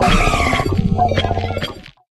Cri de Hotte-de-Fer dans Pokémon HOME.